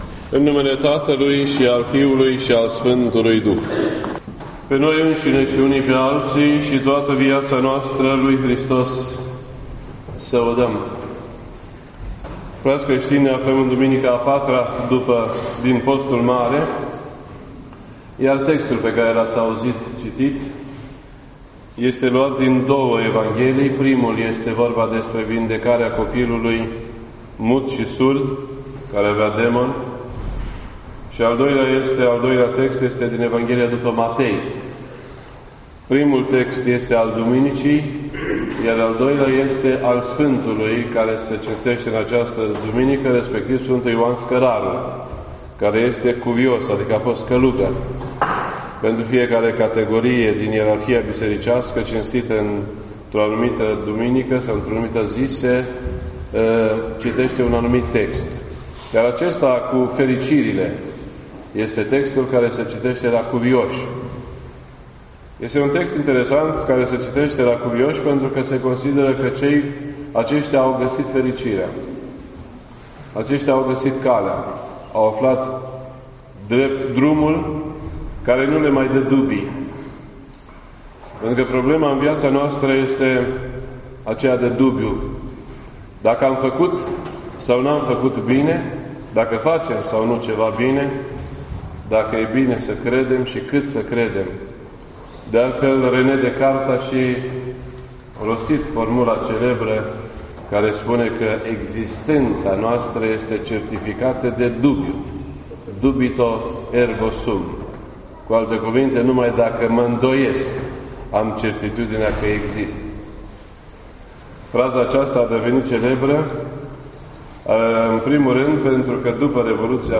This entry was posted on Sunday, March 18th, 2018 at 1:39 PM and is filed under Predici ortodoxe in format audio.